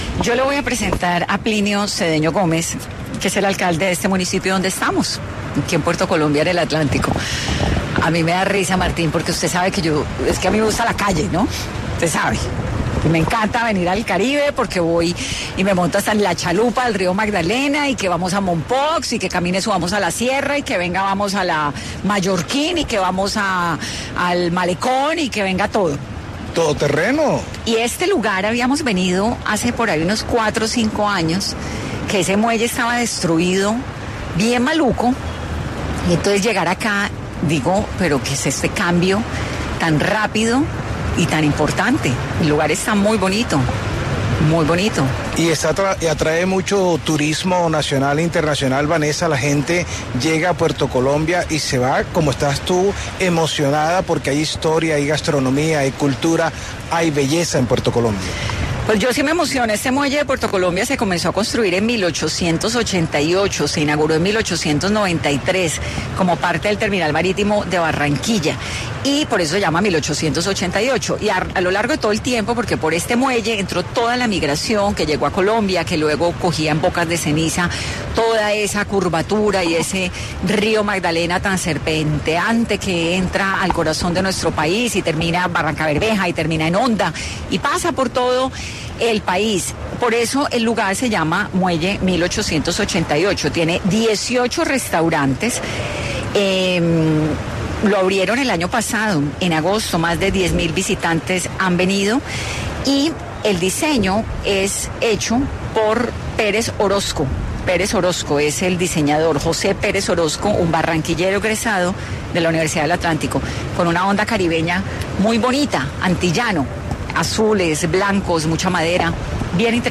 En entrevista para 10AM, el alcalde de Puerto Colombia, Plinio Cedeño, expuso el favorable panorama que tiene el municipio e hizo una especial invitación a todos los turistas.